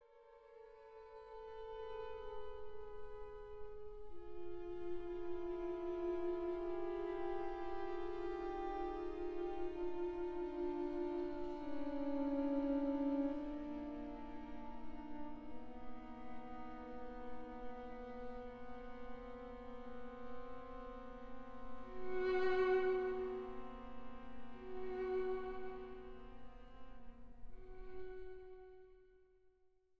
Slow, Hushed